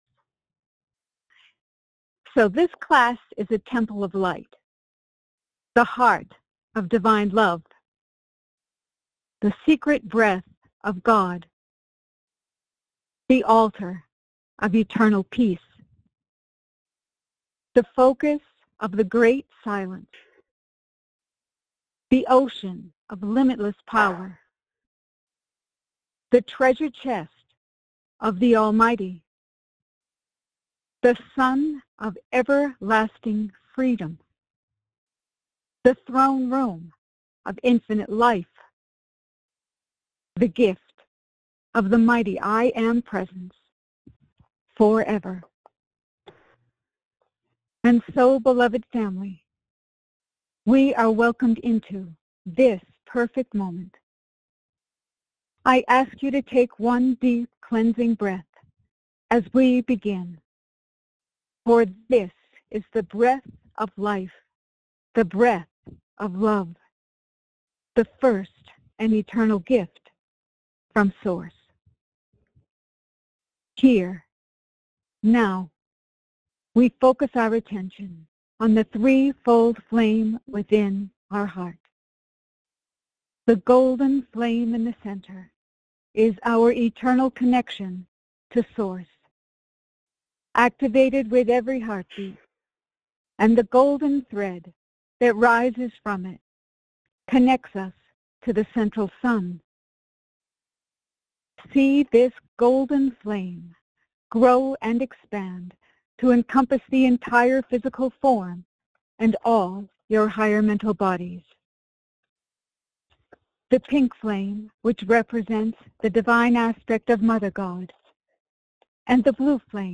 These messages were given during our Ancient Awakenings weekly Sunday conference call in Payson, AZ on December 21, 2025.